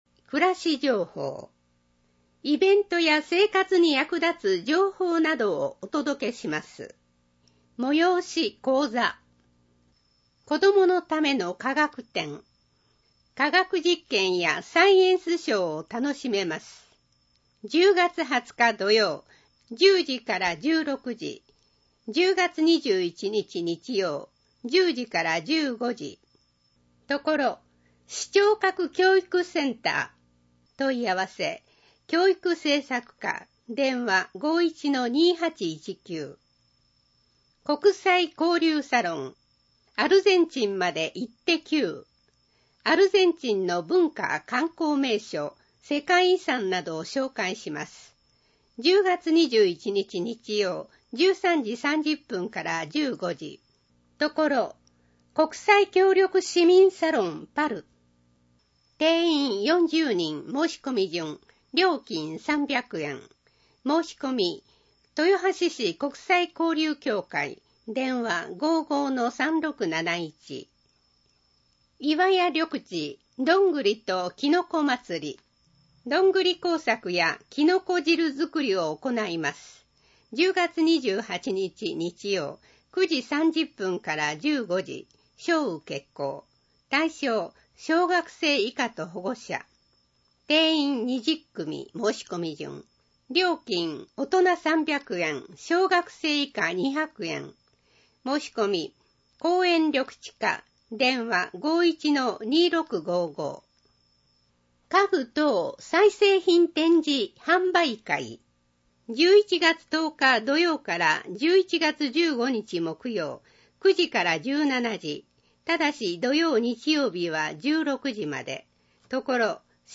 視覚障害者向けに一部読み替えています。
（音声ファイルは『音訳グループぴっち』提供）